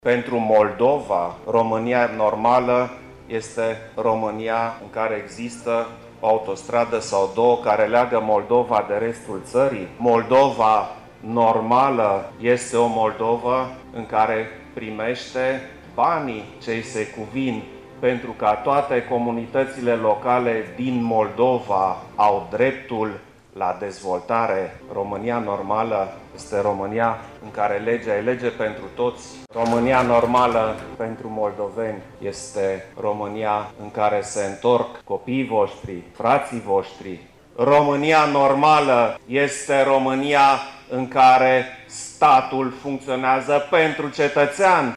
UPDATE, ora 13:30 – Preşedintele Klaus Iohannis a lansat, astăzi, la Iaşi, proiectul „România normală”, în cadrul Adunării Regionale a organizaţiilor PNL.